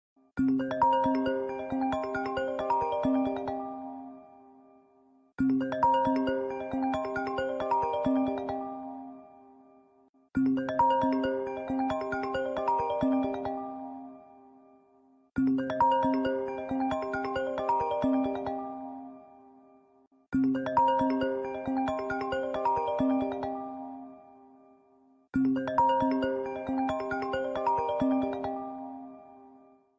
• Качество: 320, Stereo
веселые
без слов
пианино